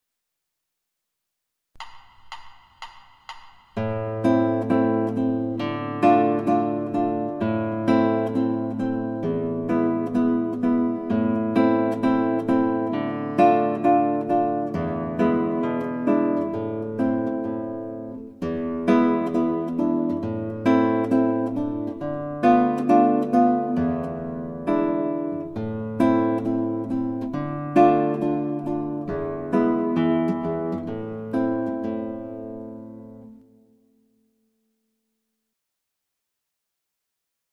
Genre klassisk
Typ av gitarr klassisk gitarr
• Instrumentering: Gitarr